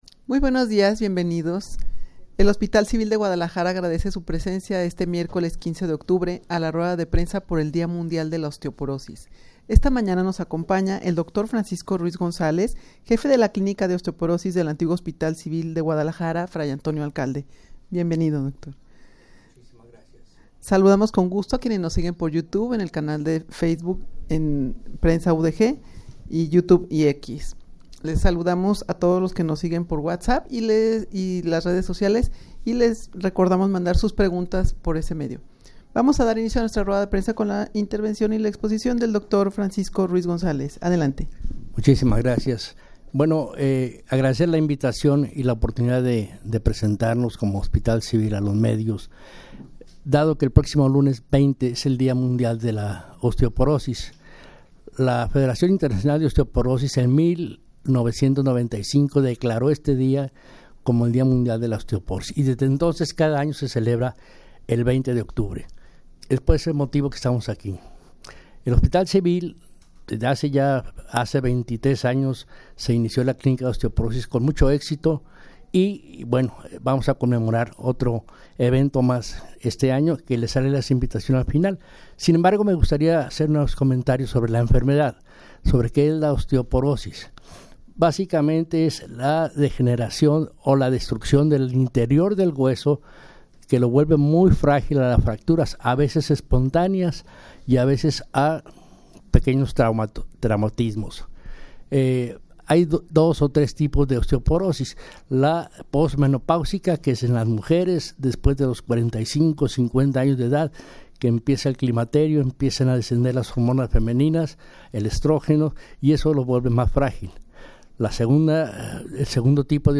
Audio de la Rueda de Prensa
rueda-de-prensa-dia-mundial-de-la-osteoporosis.mp3